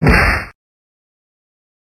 Kick
Kick sound effect for fight or game sound effects.